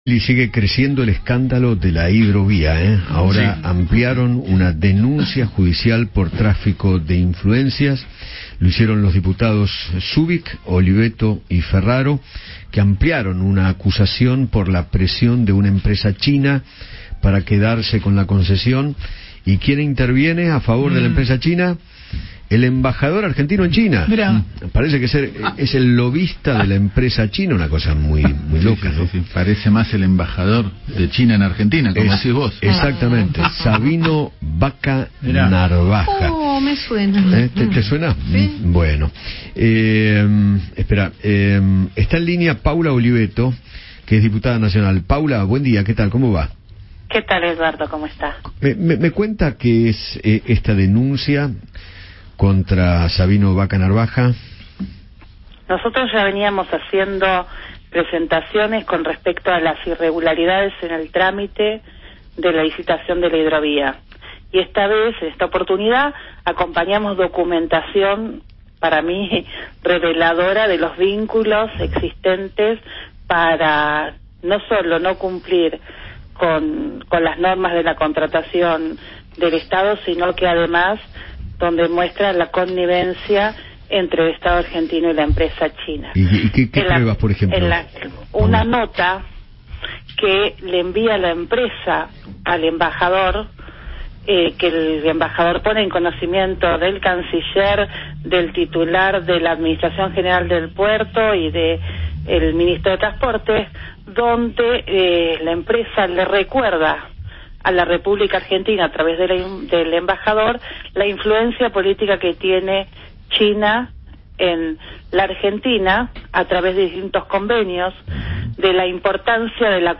Paula Oliveto, diputada nacional de la Coalición Cívica, charló con Eduardo Feinmann sobre la denuncia que realizó junto a otros diputados por tráfico de influencias.